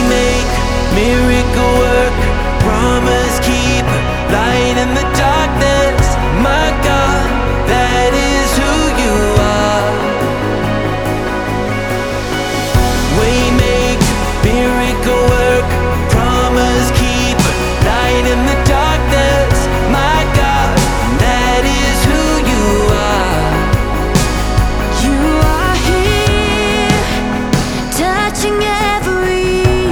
• CCM